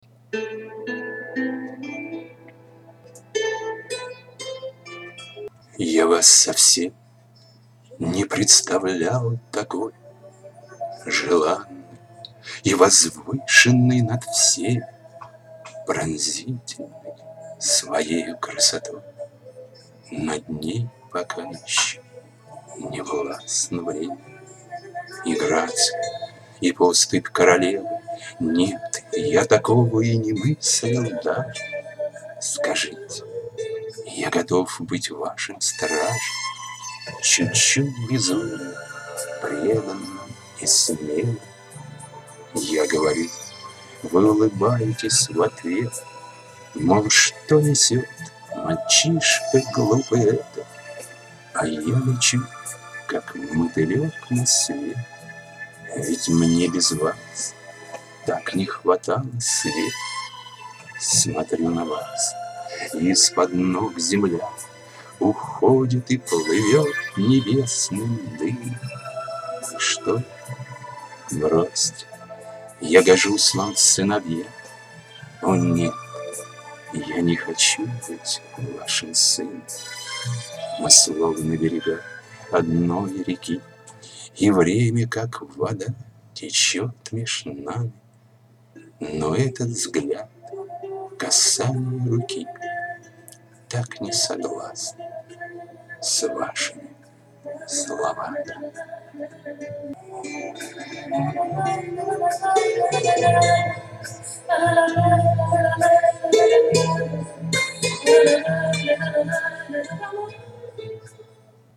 Доброй летней субботы всем завалинцам, чтобы немножко охладиться в этот жаркий день предлагаю немного аудиорифм, прошу прощения за качество это первые опытные записи.
К сожалению то примитивное устройство, которым я пользуюсь делает ямы и создается впечатление, что я проглатываю буквы, а то и слоги.